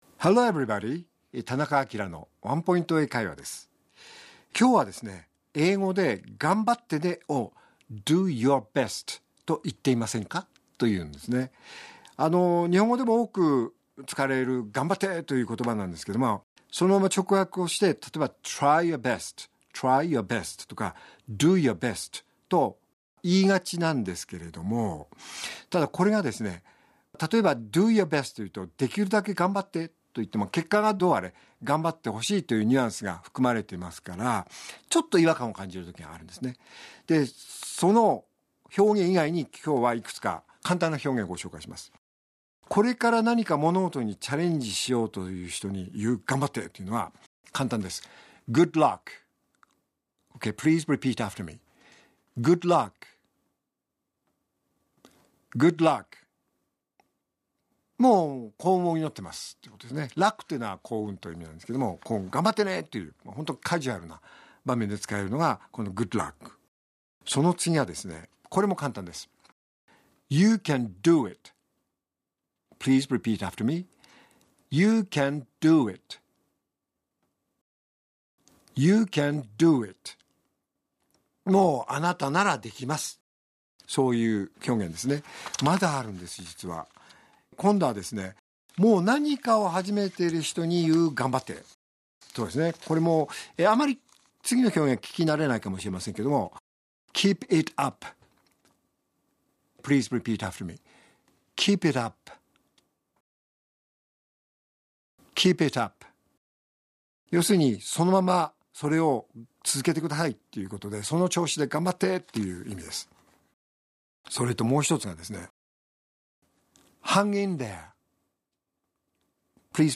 R5.8 AKILA市長のワンポイント英会話